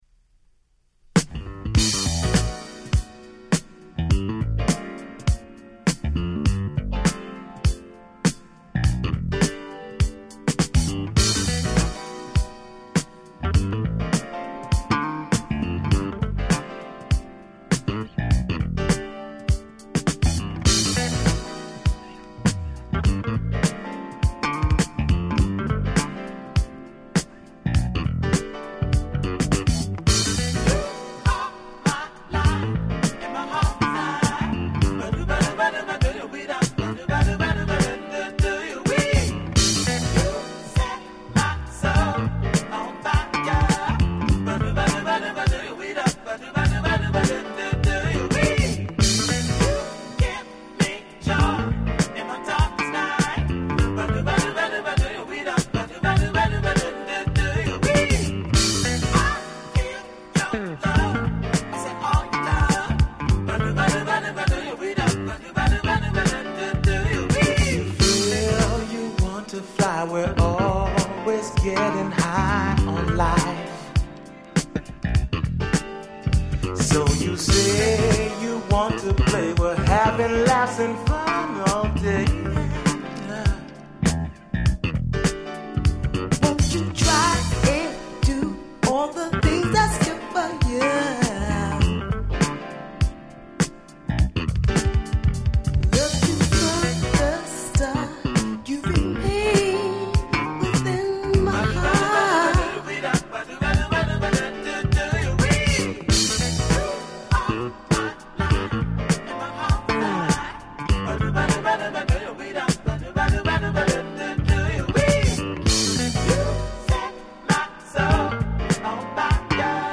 82' Rare Groove/Soul Super Classics !!